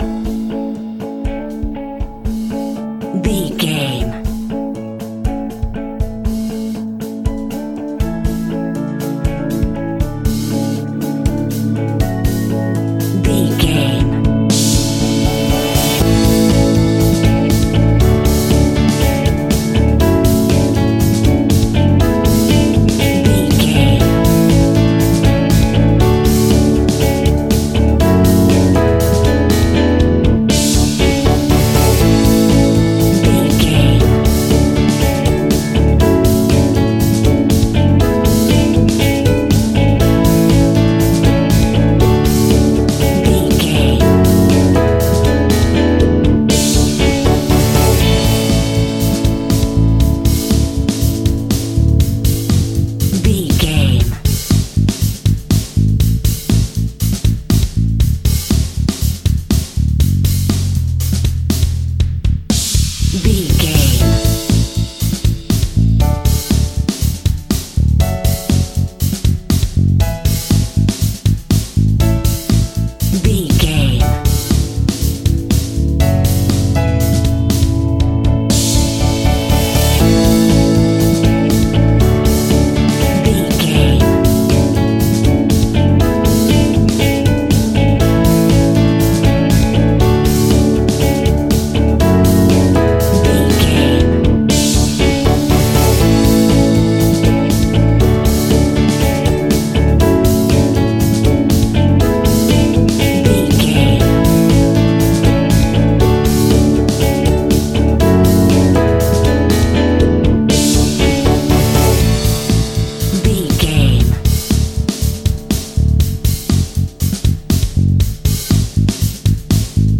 Ionian/Major
indie pop
fun
energetic
uplifting
instrumentals
upbeat
groovy
guitars
bass
drums
piano
organ